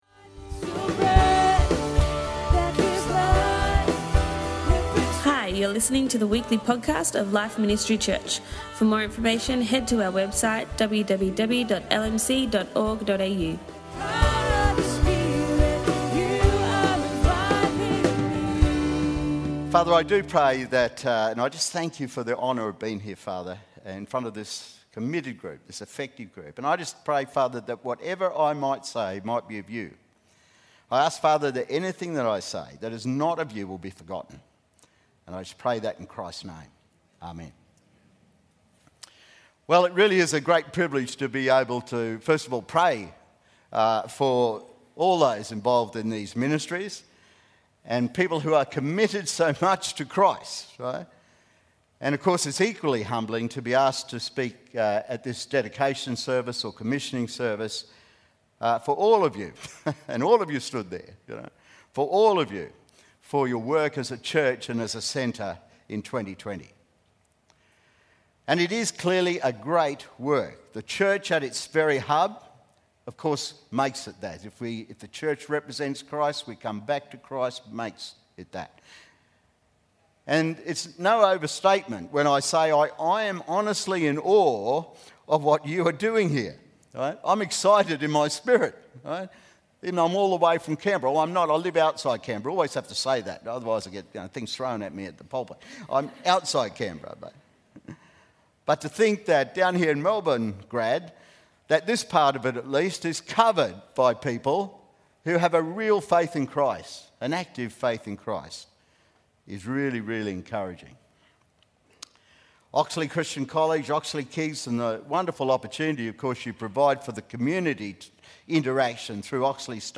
Dedication Sunday 2020